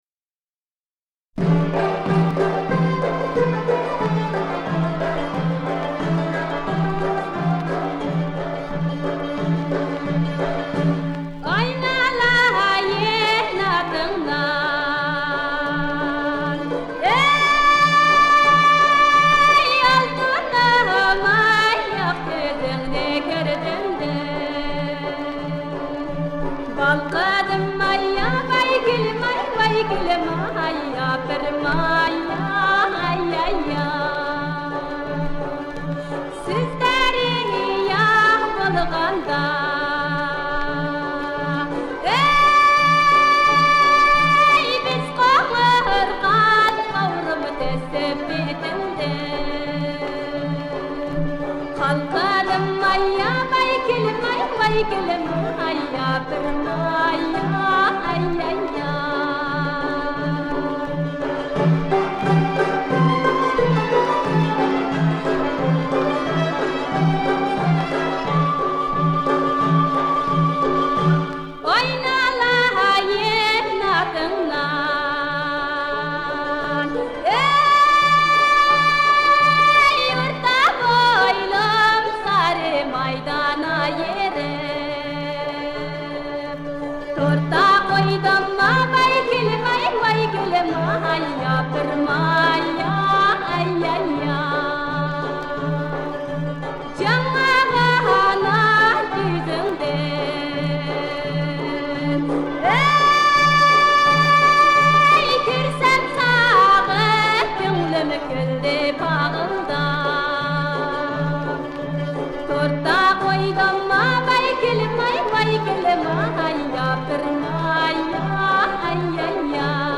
我的花儿 哈萨克民歌
中央民族歌舞团乐队伴奏 1959年录音